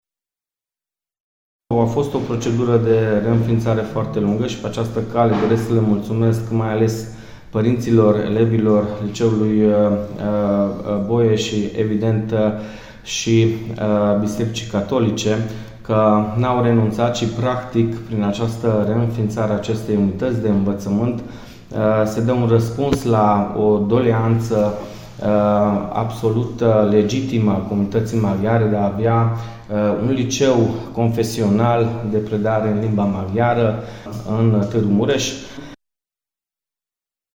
Kovács Mihály Levente, viceprimar al municipiului și președintele UDMR Târgu Mureș, spune că funcționarea Liceului Romano- Catolic contribuie la dezvoltarea educațională a localității: